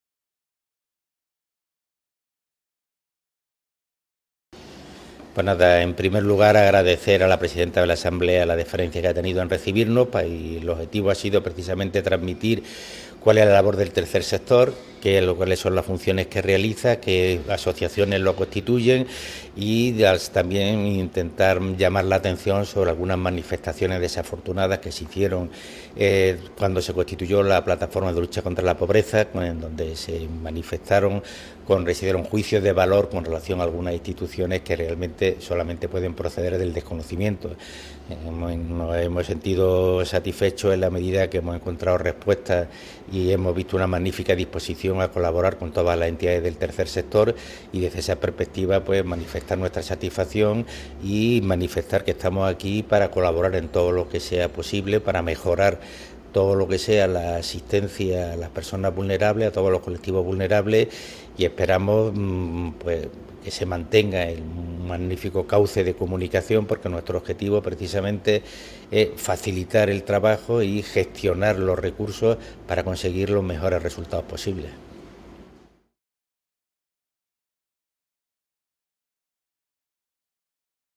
• Declaraciones de la Plataforma del Tercer Sector